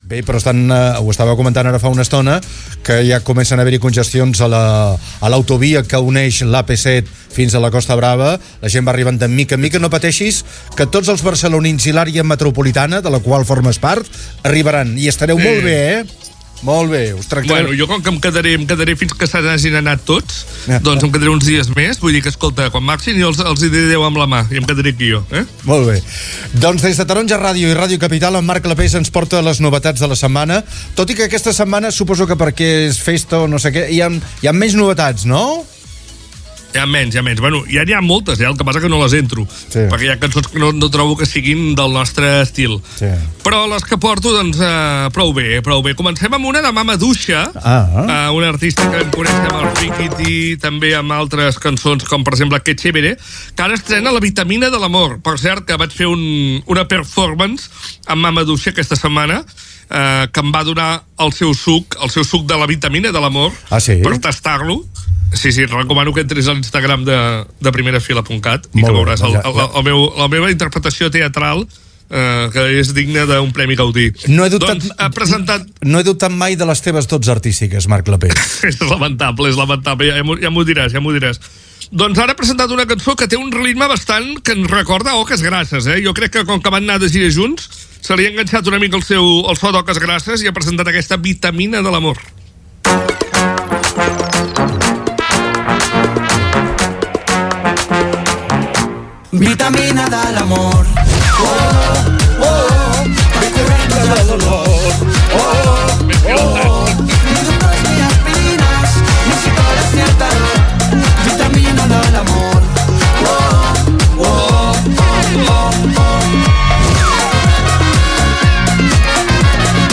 Gènere radiofònic Entreteniment
Banda FM